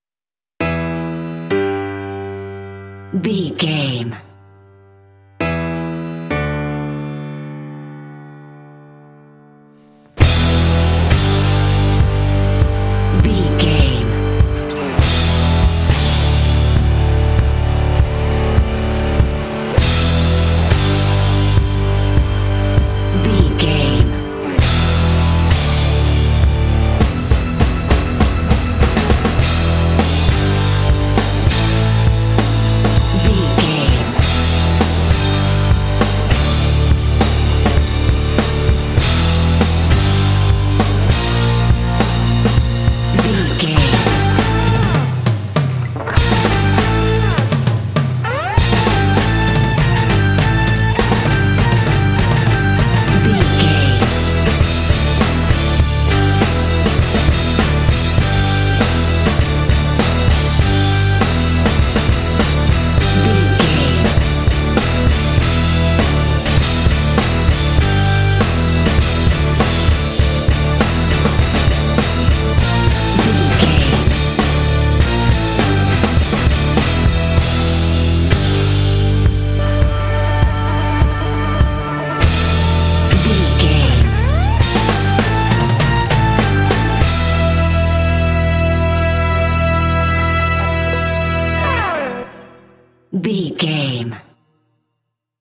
Ionian/Major
cool
powerful
energetic
heavy
electric guitar
bass guitar
drums
heavy metal
classic rock